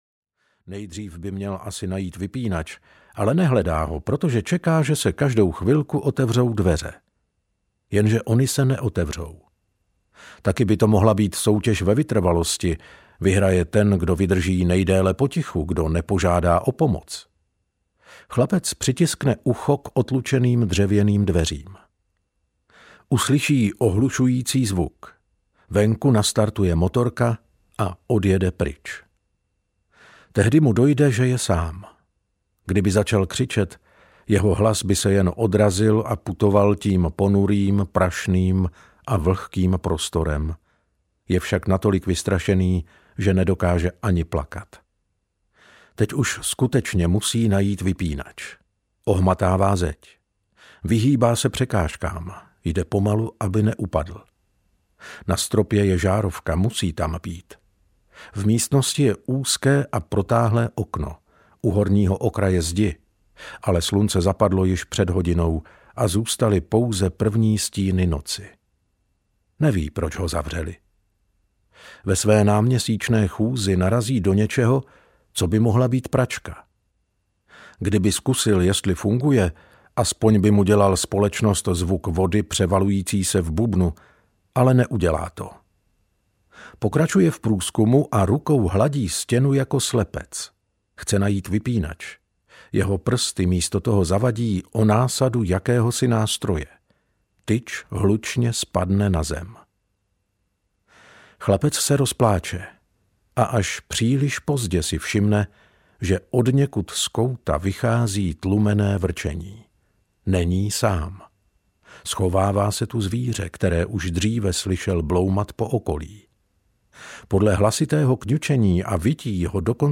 Obřad audiokniha
Ukázka z knihy